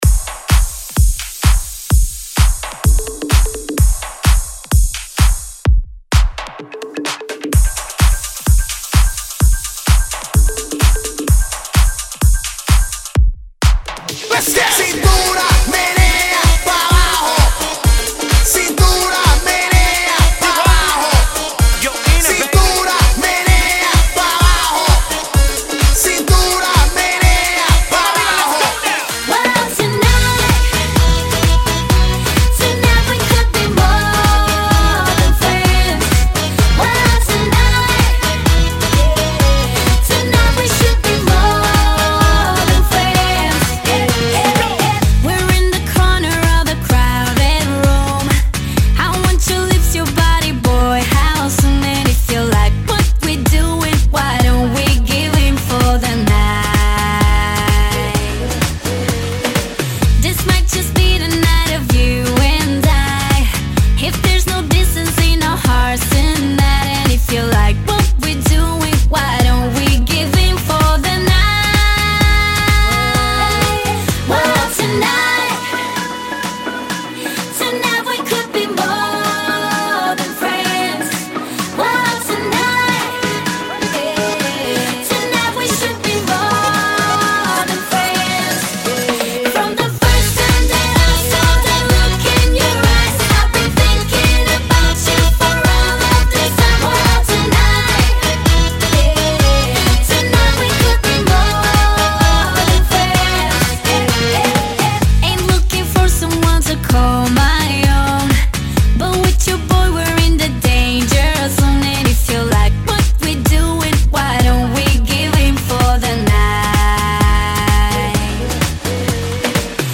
Genre: 80's Version: Clean BPM: 117 Time